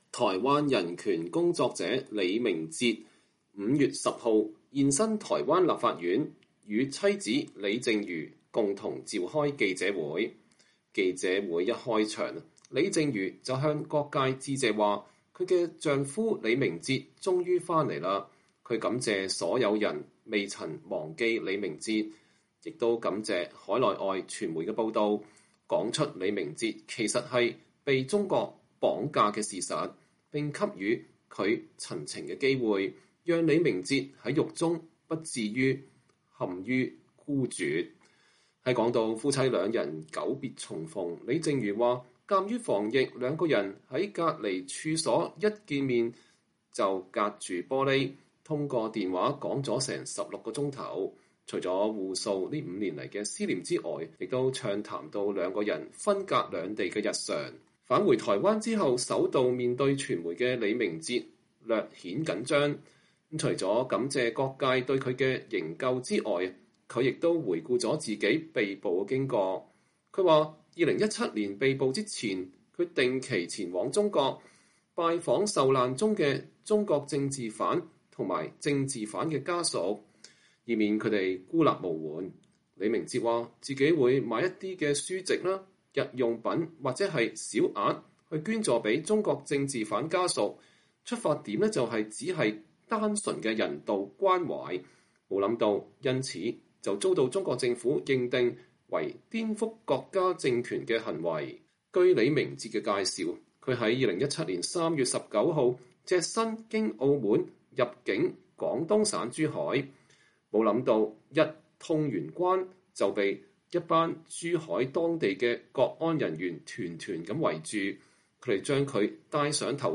台灣人權工作者李明哲週二(5月10日)在妻子李淨瑜的陪同下在台北召開記者會，講述他遭到中國審判和囚禁五年的經歷。李明哲2017年3月經澳門入境中國後，遭中國公安逮捕，同年11月被中國當局以“顛覆國家政權罪”判處五年。